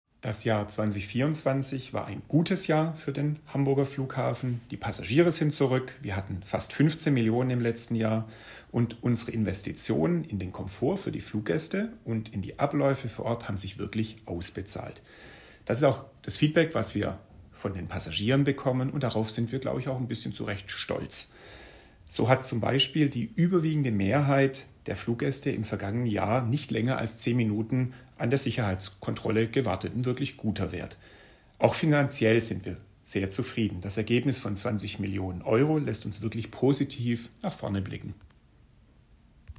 Bilanz Pressekonferenz 2025
Audio-Statement